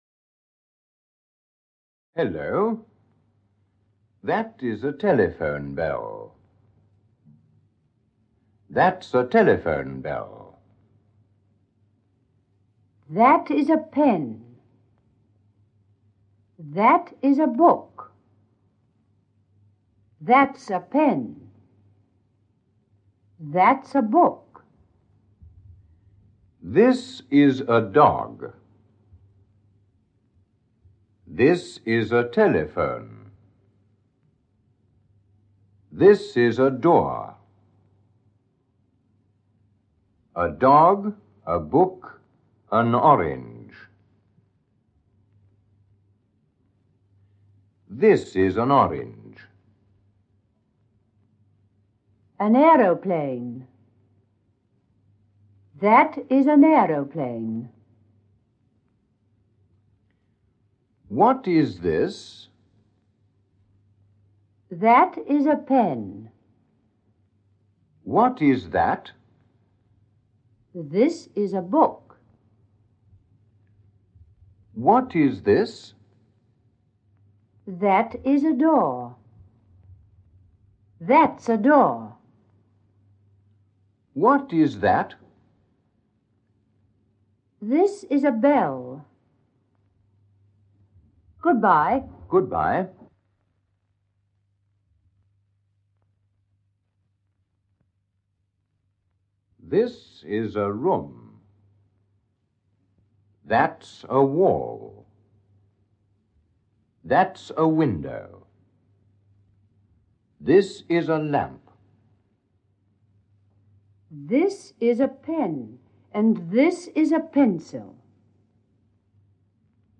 Get £1.56 by recommending this book 🛈 Voici un cours de langue anglaise pour tous, adapté pour un apprentissage uniquement audio. Vous trouverez des exemples et des modèles de conversations, à écouter et à répéter, de multiples phrases et dialogues qui mettent en scène des situations de la vie quotidienne, ainsi que des exercices d'écoute et de répétition.